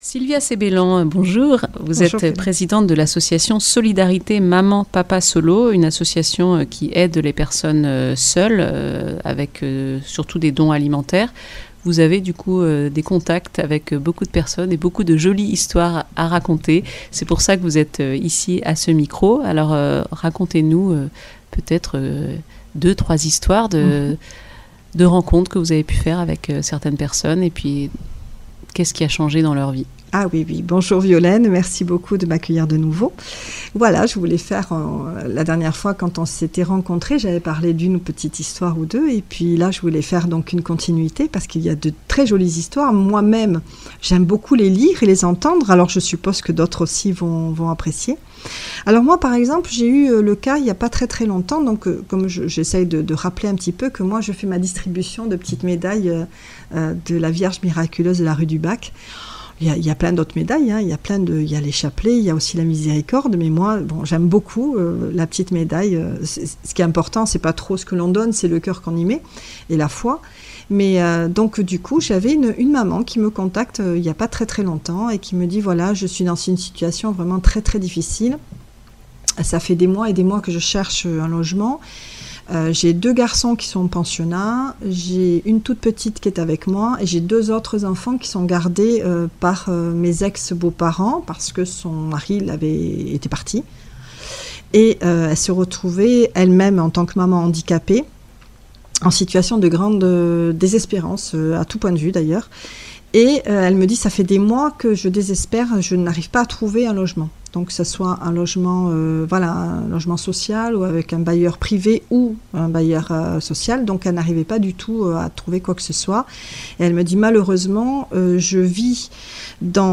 Témoignage